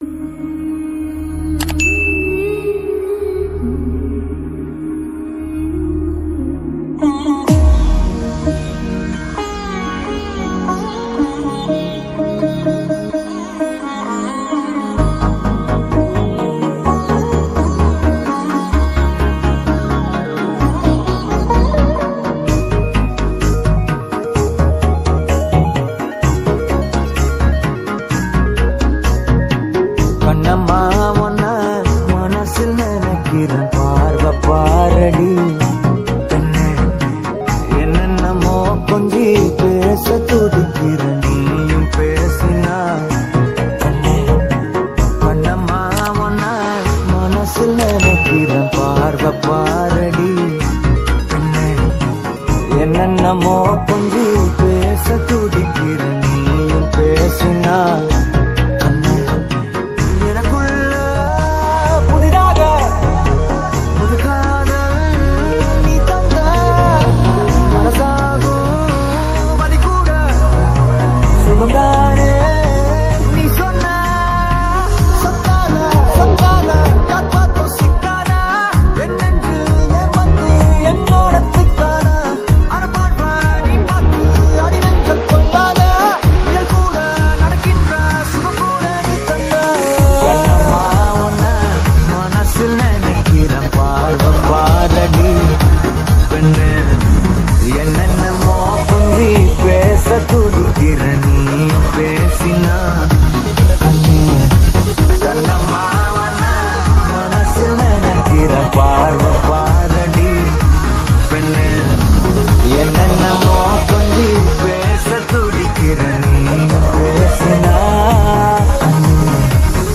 ALL TAMIL DJ REMIX » Tamil 8D Songs